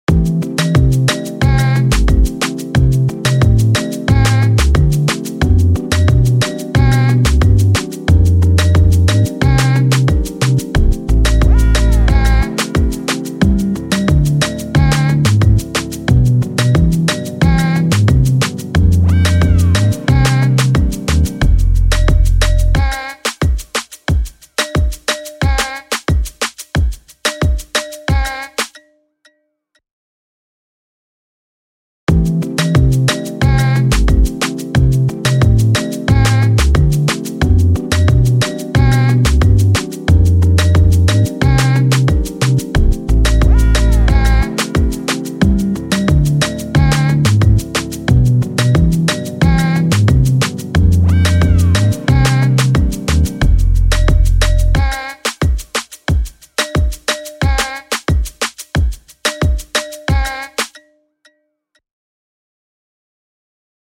智能作曲音频试听（来自网友十分钟创作的片段）